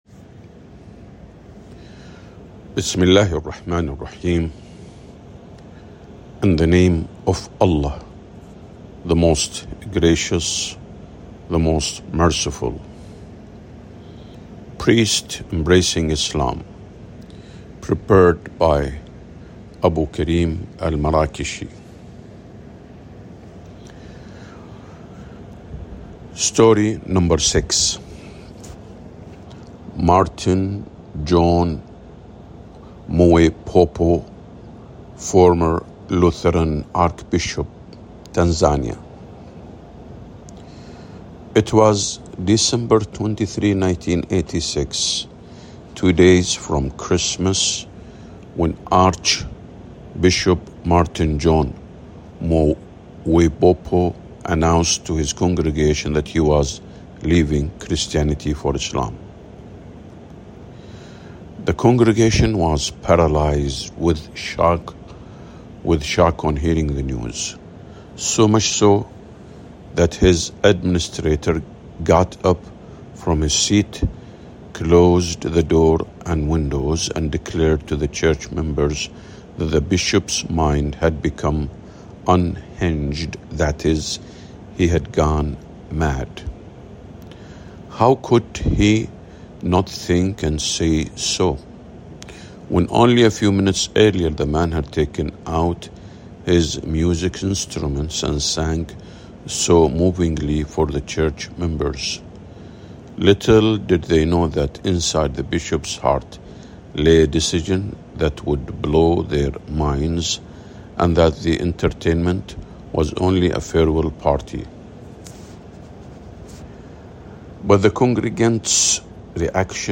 Audiobook
priests-embracing-islam_audiobook_english_6.mp3